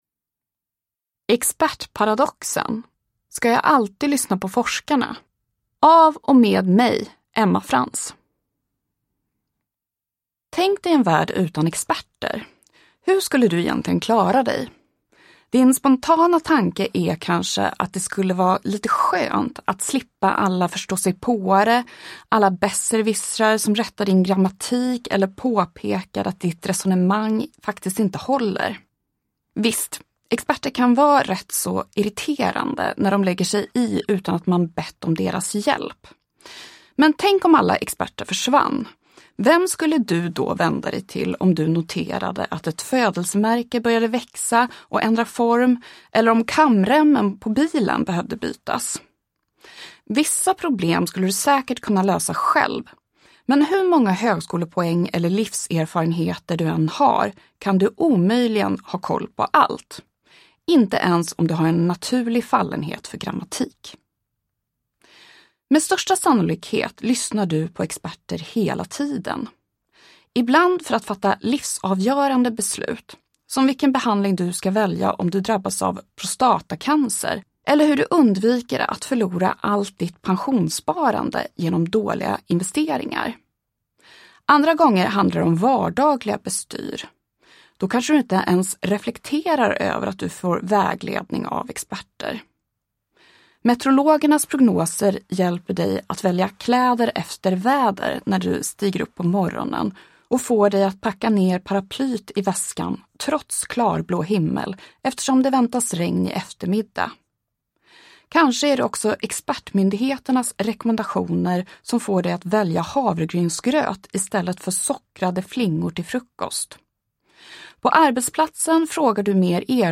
Expertparadoxen : ska jag alltid lyssna på forskarna? – Ljudbok
Uppläsare: Emma Frans